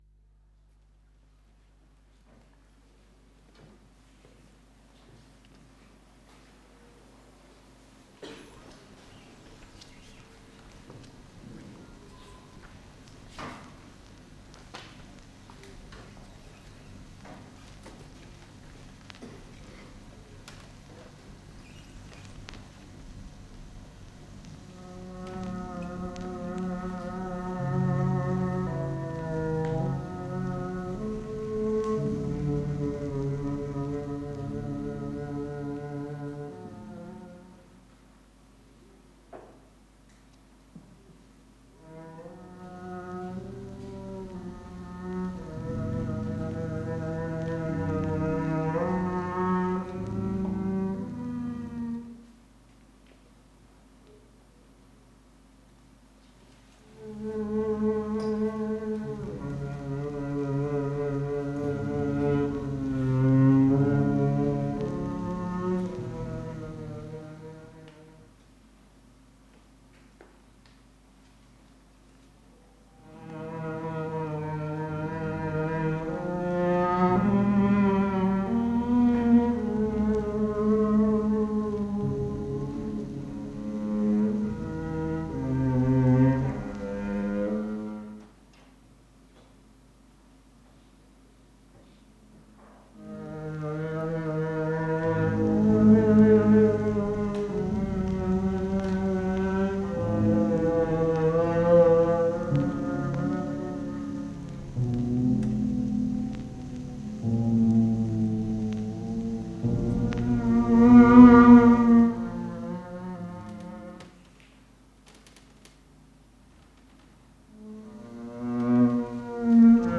Venue Tramway, Glasgow
turntable